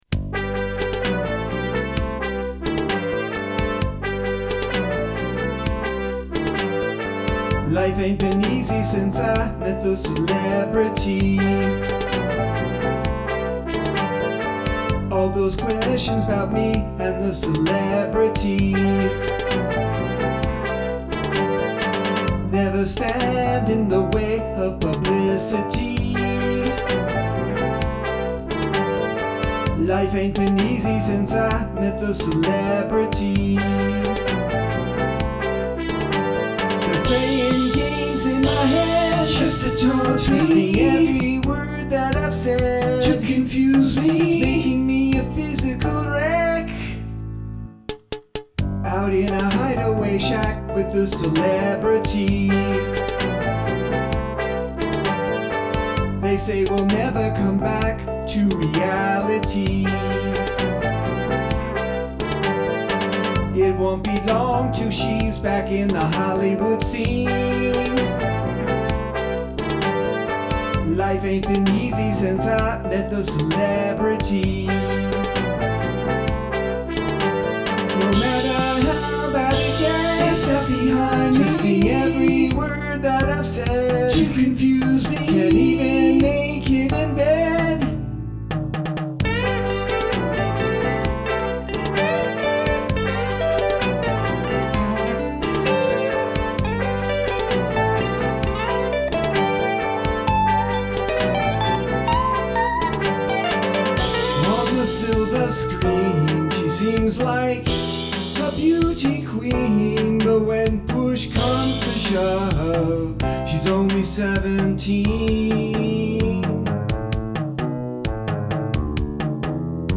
guitar, bass guitars, Chapman Stick Touchboard